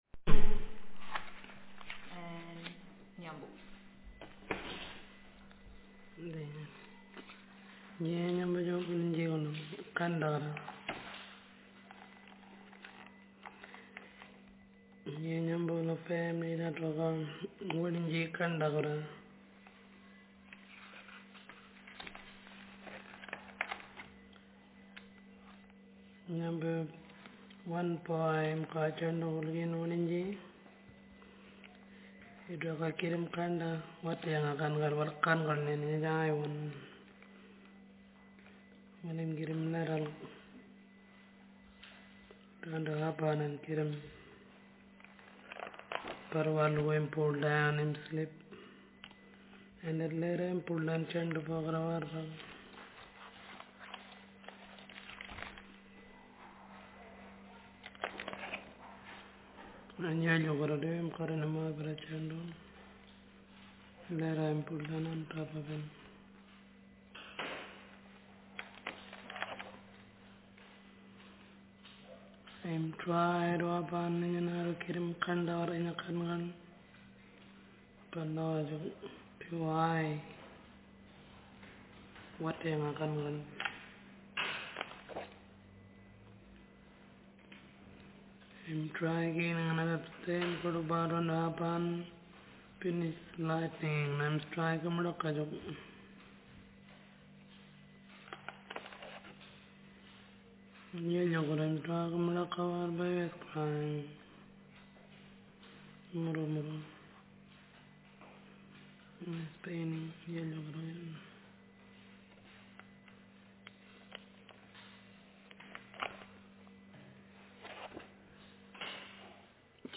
Speaker sex f Text genre stimulus retelling